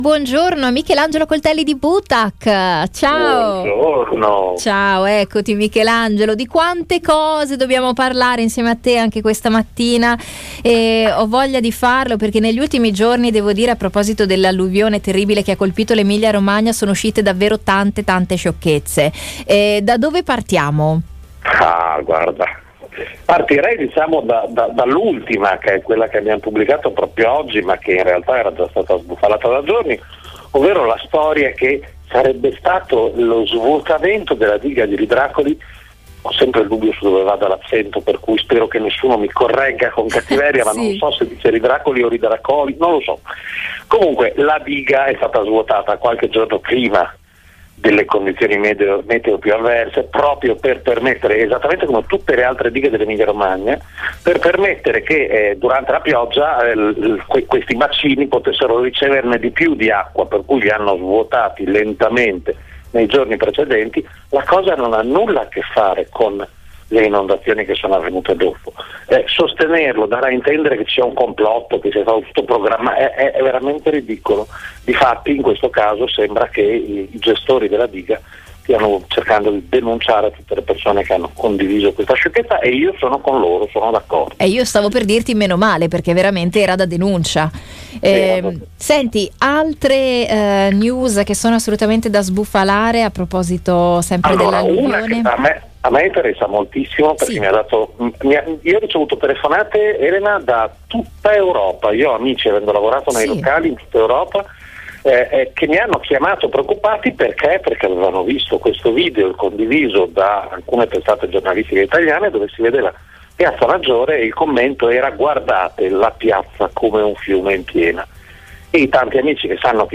Ecco l’approfondimento durante il programma Passepartout: